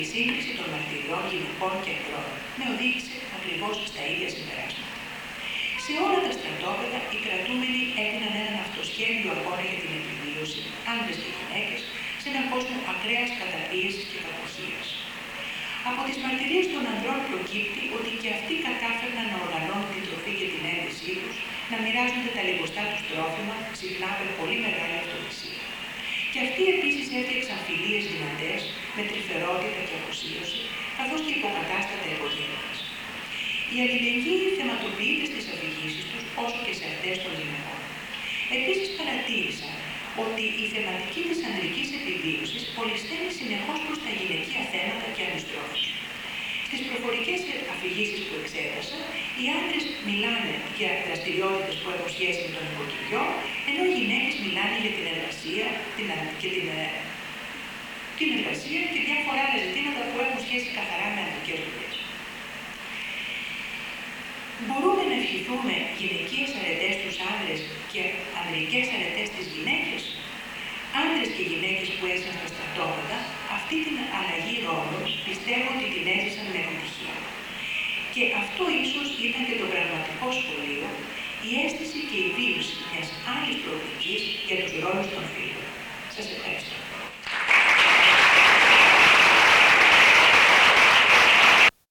Techni: Αφηγήσεις γυναικών για τον πόλεμο και την κατοχή